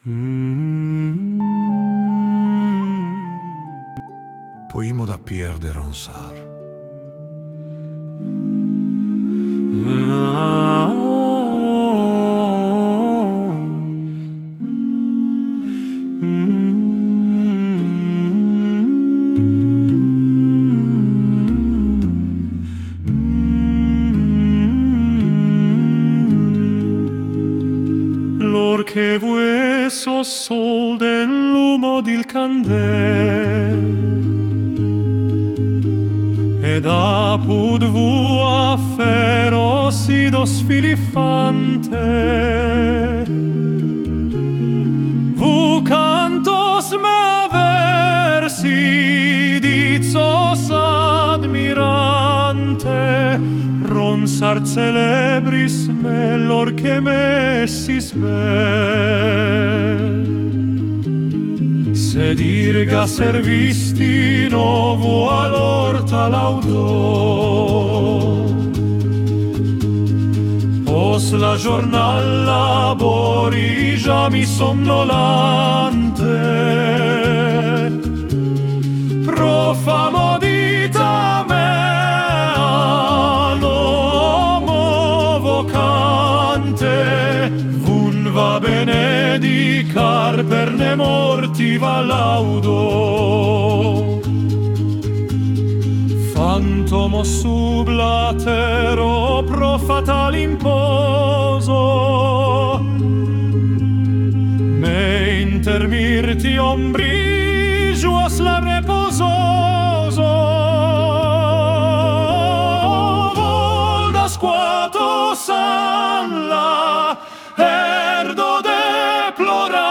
kansoni bosa nova